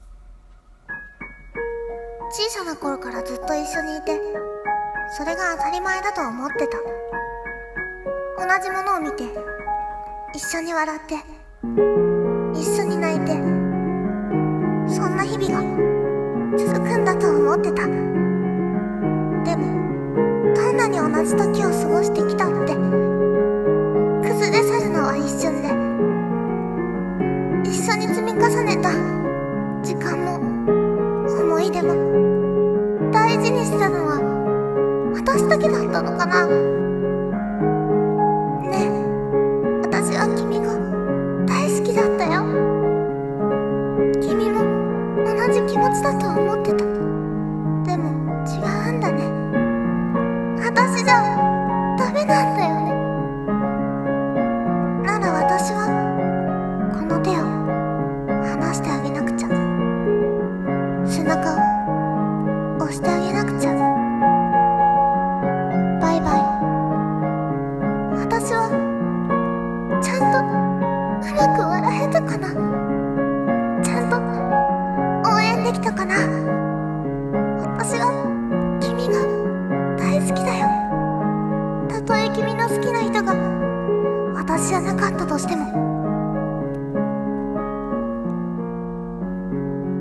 声劇 朗読